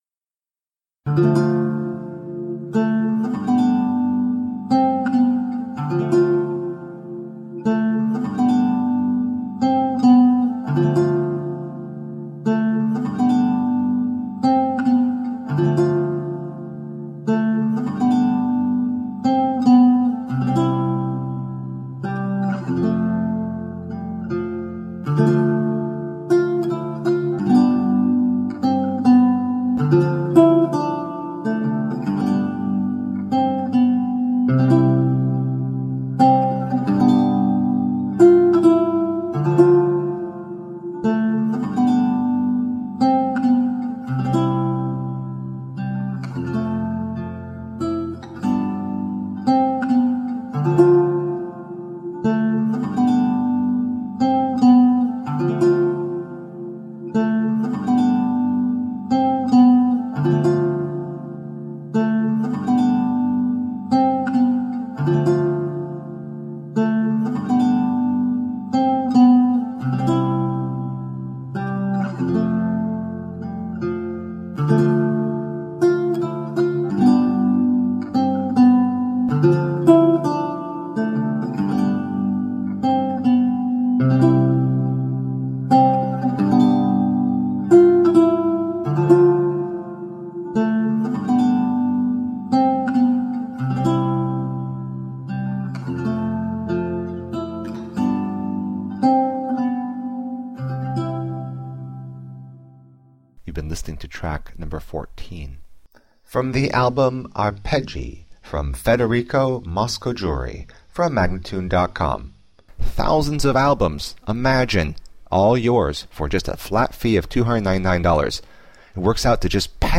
Original and evocative lute music.